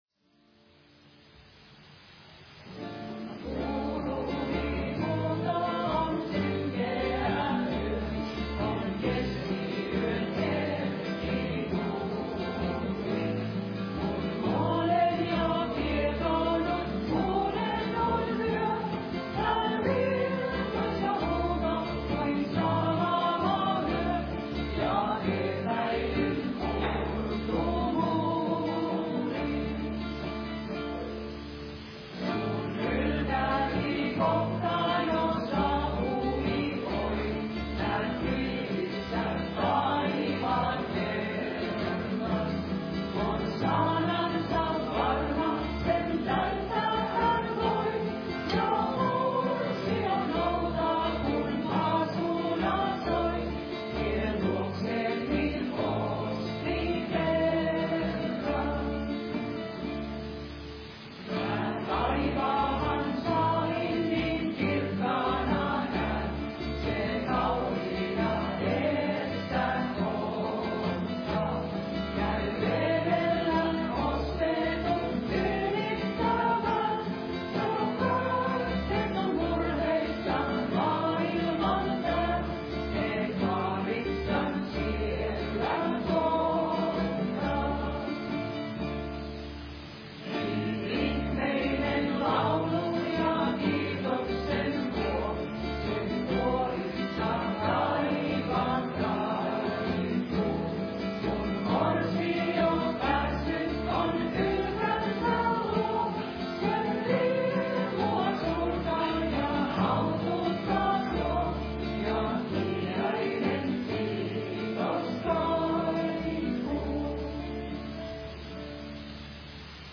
Siiloan-seurakunnan Raamattutunnit podcastina.